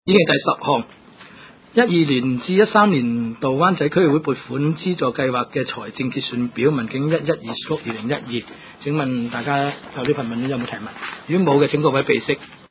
区议会大会的录音记录
湾仔区议会第七次会议